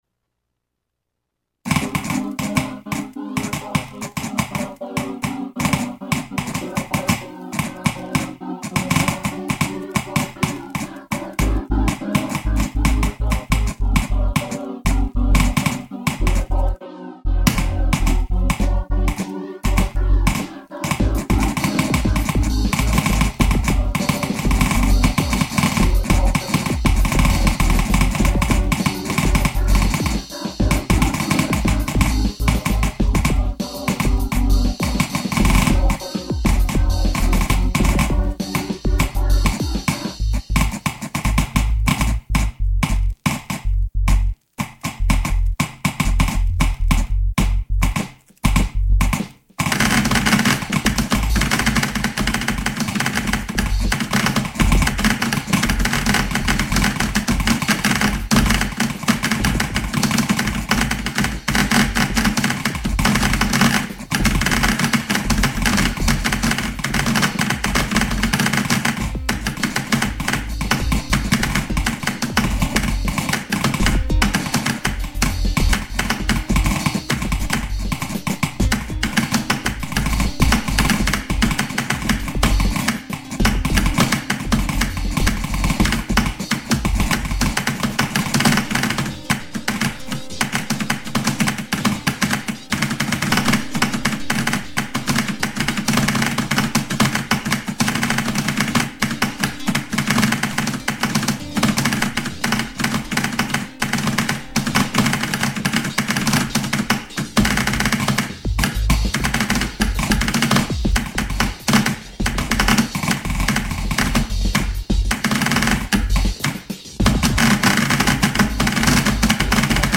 the game audio desyncs towards the end.